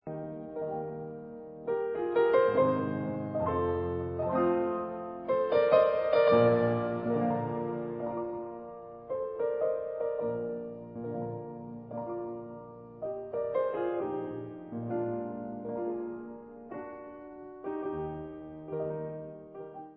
sledovat novinky v kategorii Vážná hudba